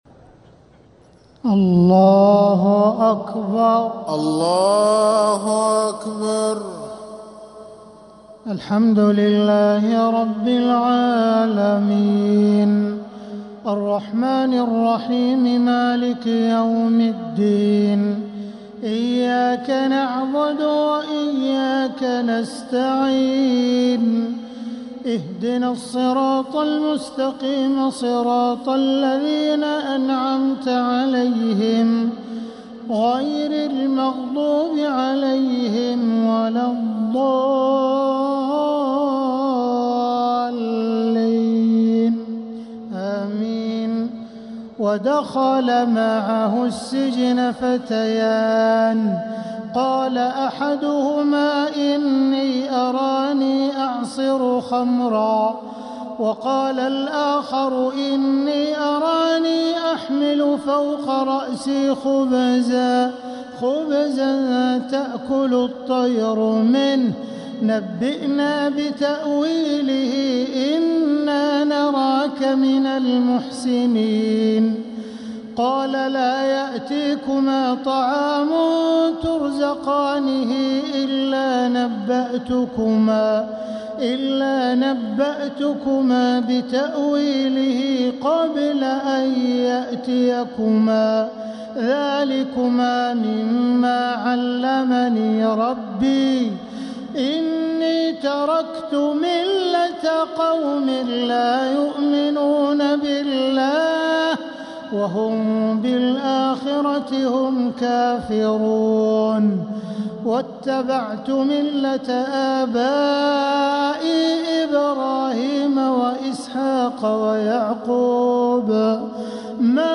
تراويح ليلة 16 رمضان 1447هـ من سورة يوسف {36-57} Taraweeh 16th night Ramadan 1447H Surah Yusuf > تراويح الحرم المكي عام 1447 🕋 > التراويح - تلاوات الحرمين